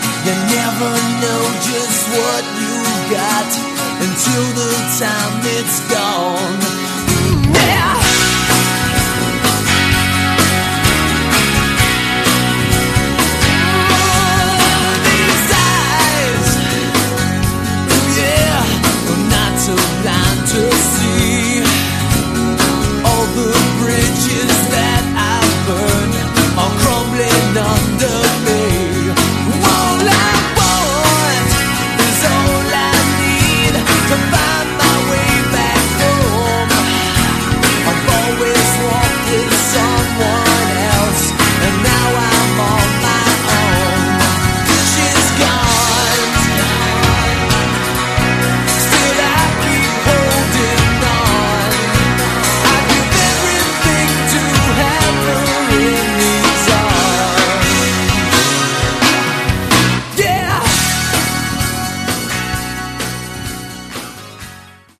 Category: Hard Rock
lead vocals, lead guitar
bass guitar, backup vocals
drums, keyboard programming, backing vocals